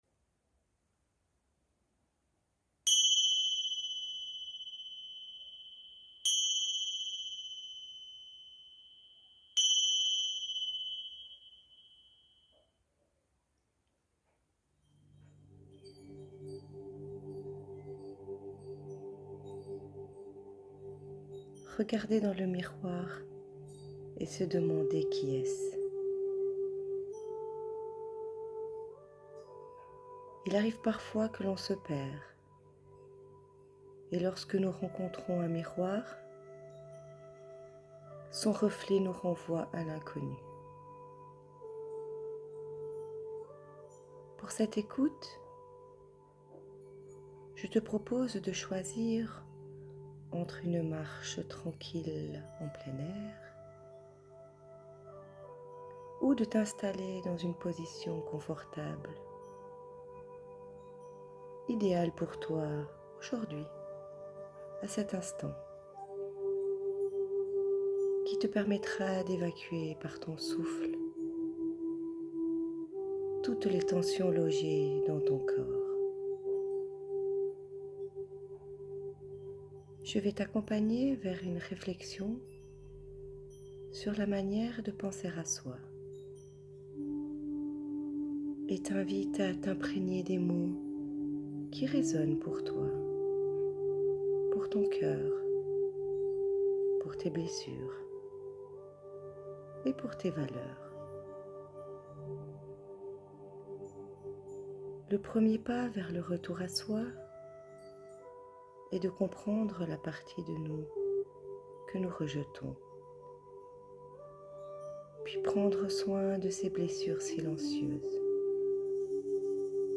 méditation guidée, prendre soin de soi, audio pour détente